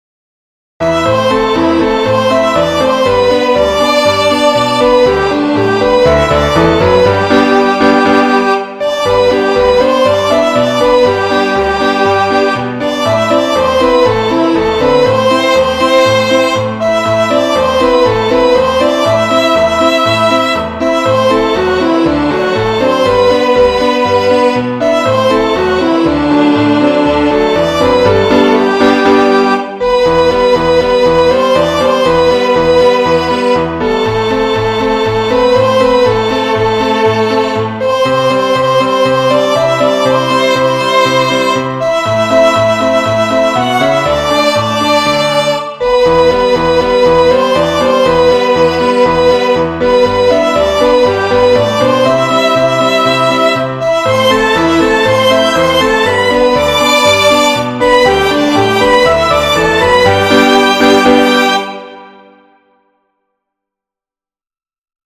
3  MARCHAS POPULARES para 2026